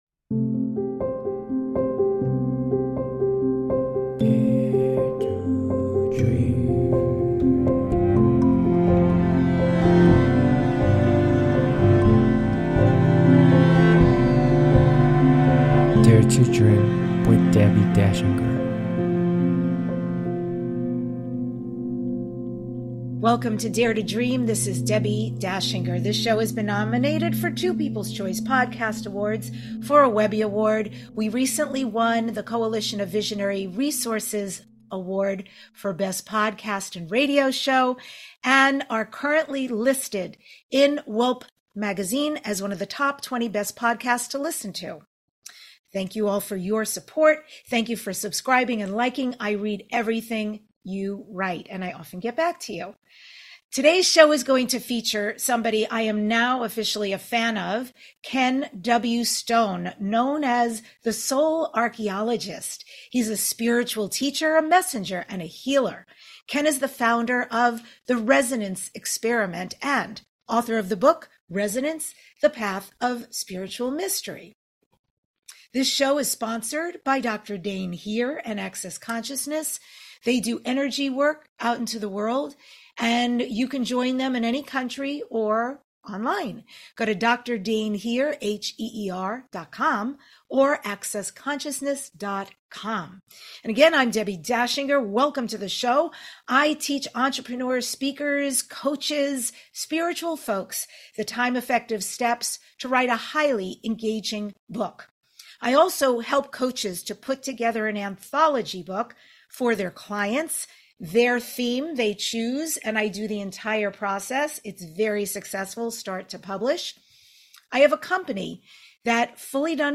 Talk Show Episode, Audio Podcast, Dare To Dream and Guest